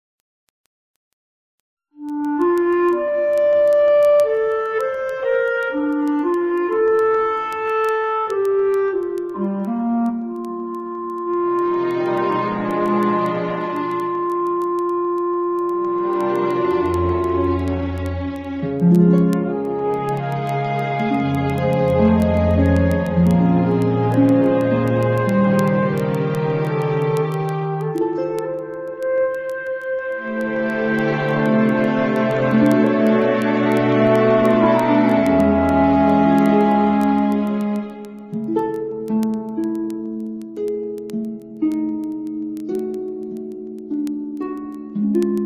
NOTE: Background Tracks 10 Thru 18